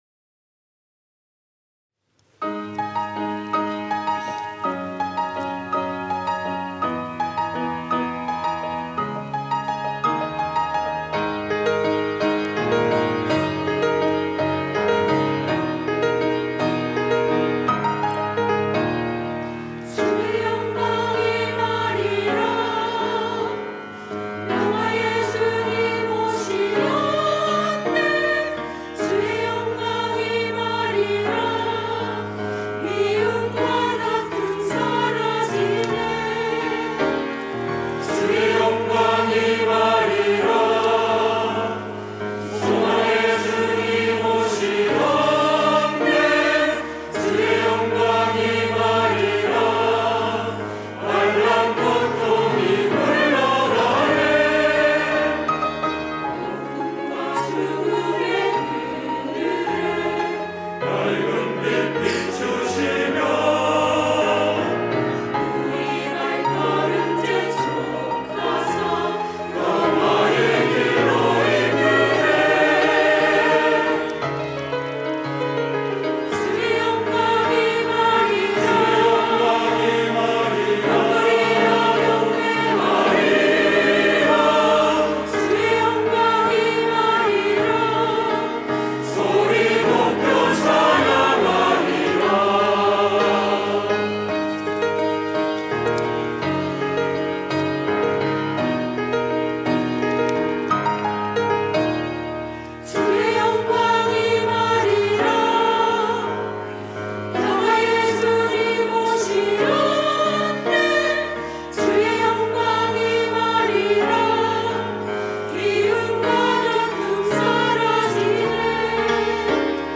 할렐루야(주일2부) - 주의 영광 임하리라
찬양대